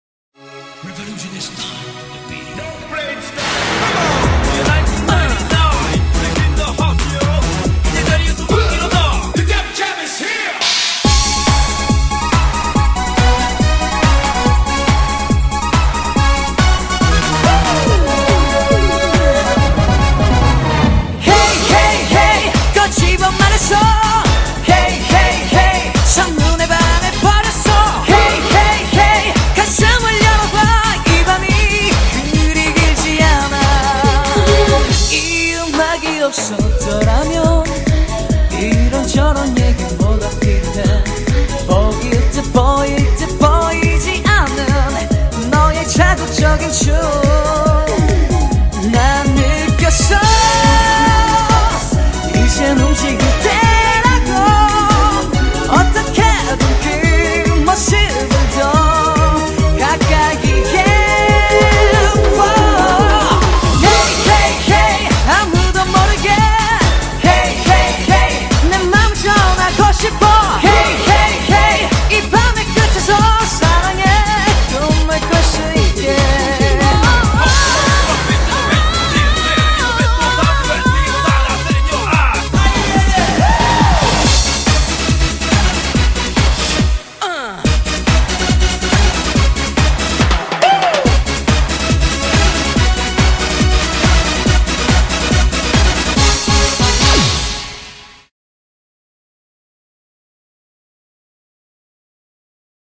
BPM161--1
Audio QualityPerfect (High Quality)